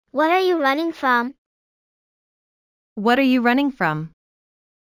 合成音声元：音読さん
２つめは are が do に聞こえます。
2つ目の人は、what の ｔ が d に聞こえて合体していますが、are と do は母音が違うので、ダーとドゥーでわかります。